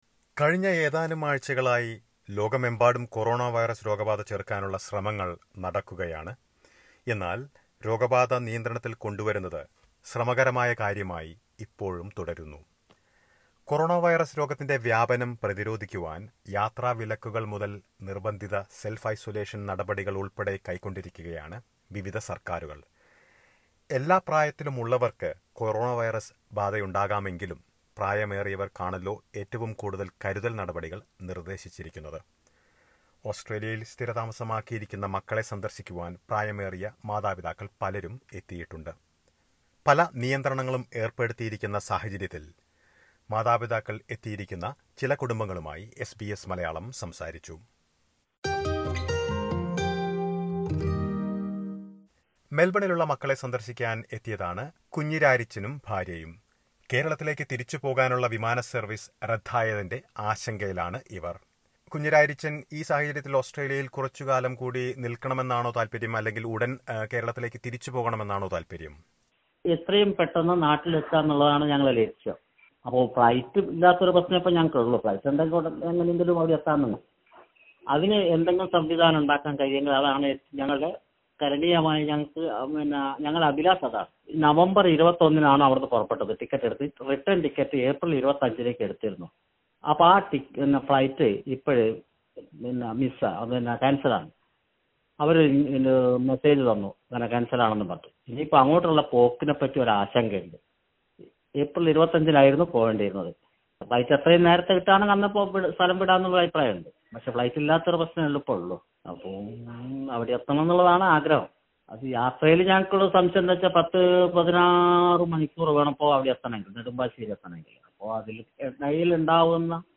കൊറോണവൈറസ് ചെറുക്കുന്നത് ലക്ഷ്യമിട്ട് നടപ്പിലാക്കിയിരിക്കുന്ന നിയന്ത്രണങ്ങൾ കാരണവും വിമാന സർവീസുകൾ റദ്ദാക്കിയതിനെ തുടർന്നും ഓസ്‌ട്രേലിയയിൽ മക്കളെ സന്ദർശിക്കുവാൻ എത്തിയിരിക്കുന്ന മാതാപിതാക്കളിൽ പലർക്കും തിരിച്ച് കേരളത്തിലേക്ക് മടങ്ങാൻ കഴിഞ്ഞിട്ടില്ല. എത്രയും വേഗം നാട്ടിലേക്ക് മടങ്ങണമെന്നാണ് ഇവരിൽ പലരുടെയും ആഗ്രഹം. ഇവരിൽ ചിലരുമായി സംസാരിക്കുന്നത് കേൾക്കാം മുകളിലെ പ്ലെയറിൽ നിന്ന്.